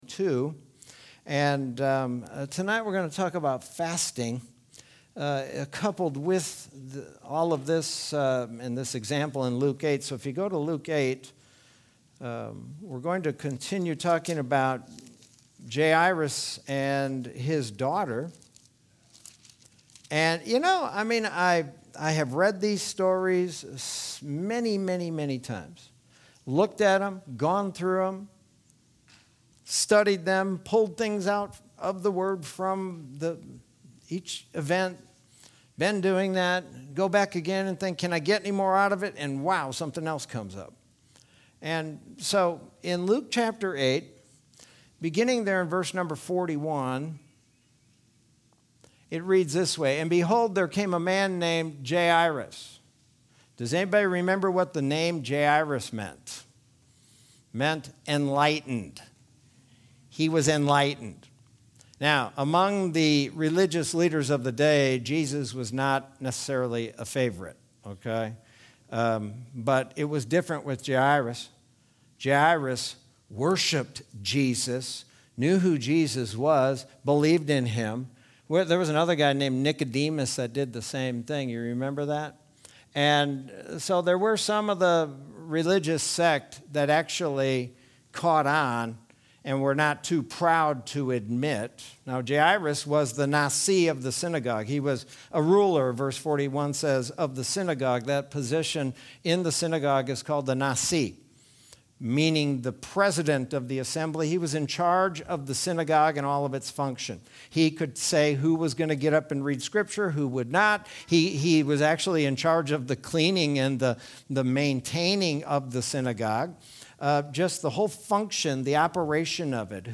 Sermon from Wednesday, July 14th, 2021.